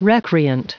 Prononciation du mot recreant en anglais (fichier audio)
Prononciation du mot : recreant